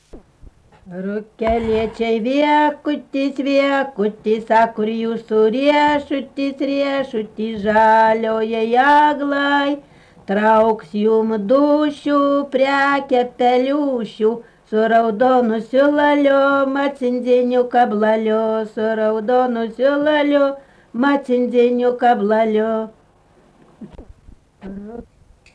daina, vaikų
Erdvinė aprėptis Jonava Bagdoniškis
Atlikimo pubūdis vokalinis